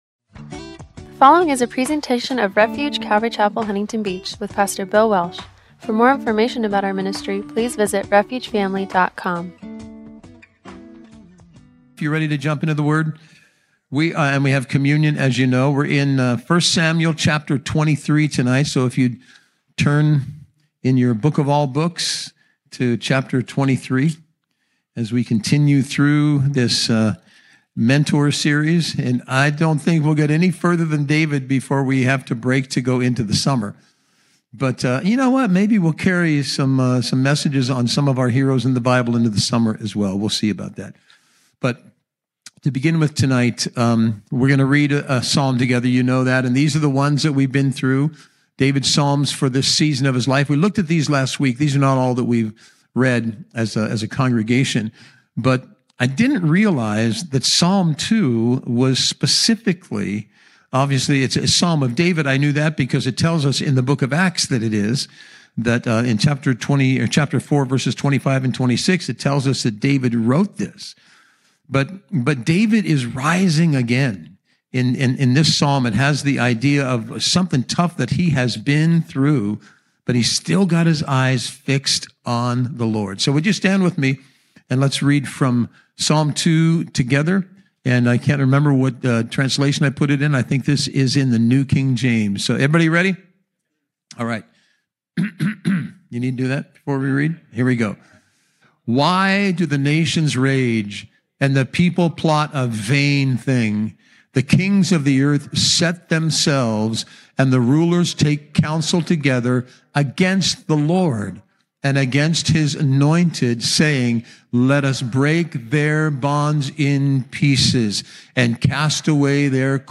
“King David to the Rescue”–1 Samuel 23 – Audio-only Sermon Archive
Service Type: Wednesday Night